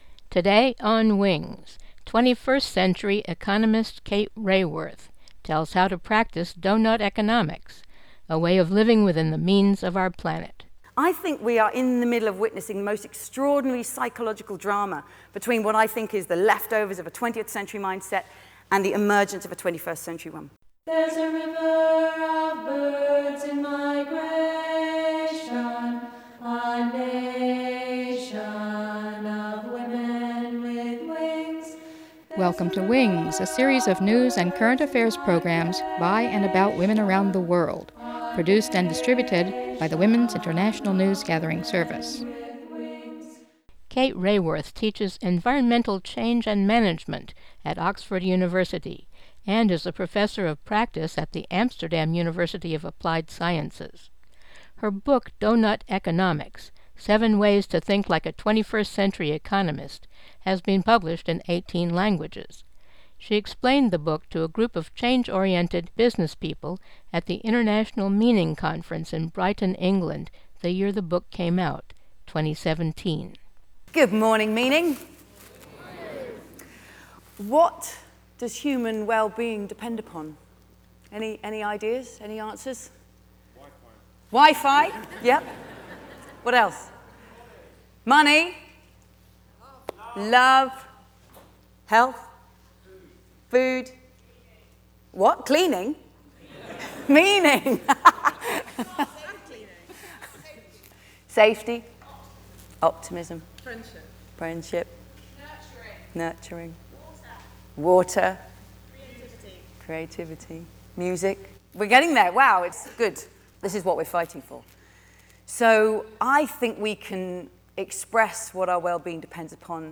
Meaning Conference audio